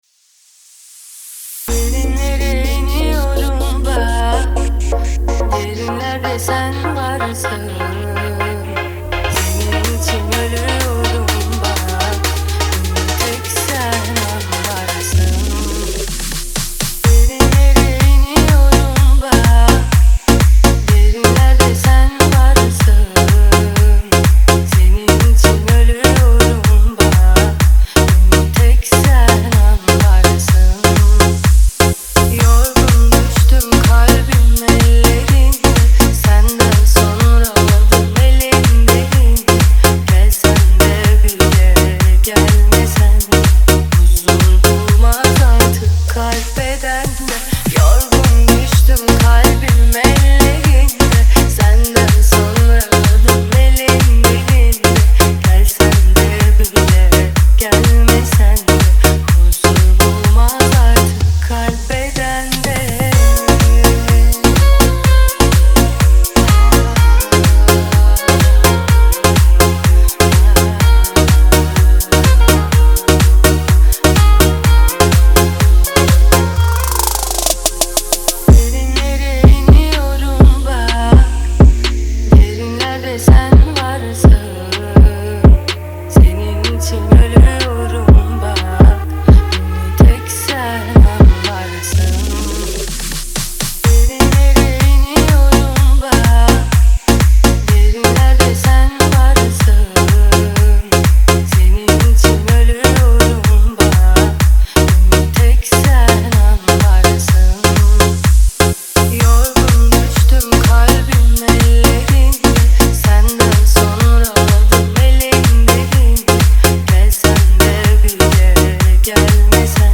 آهنگ ترکیه ای آهنگ غمگین ترکیه ای آهنگ هیت ترکیه ای ریمیکس
دانلود نسخه ریمیکس همین موزیک